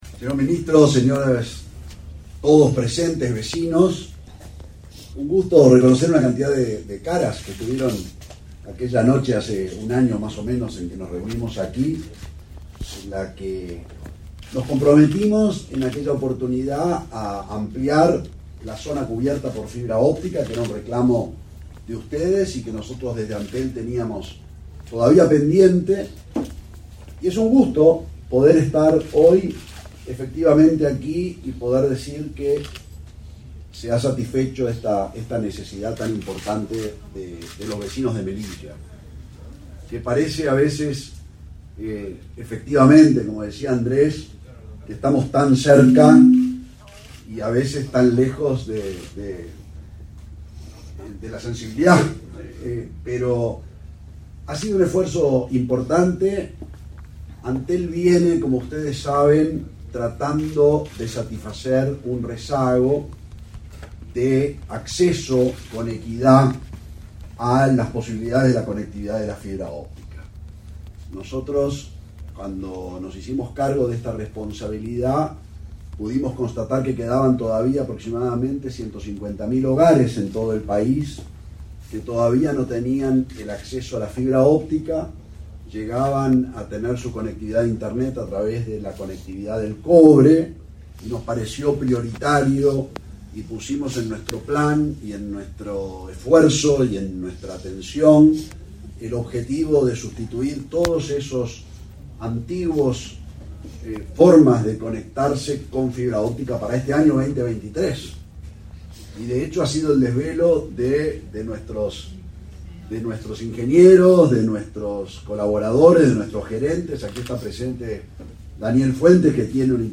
Conferencia de prensa por inauguración de cámaras de videovigilancia en la zona de Melilla
Conferencia de prensa por inauguración de cámaras de videovigilancia en la zona de Melilla 03/05/2023 Compartir Facebook X Copiar enlace WhatsApp LinkedIn En la zona de Melilla, Montevideo, quedaron instaladas cámaras de videovigilancia. En un acto realizado este 3 de mayo, el ministro del Interior, Luis Alberto Heber, y el presidente de Antel, Gabriel Gurméndez, destacaron la iniciativa.